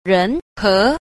6. 人和 – rén hé – nhân hòa
ren_he.mp3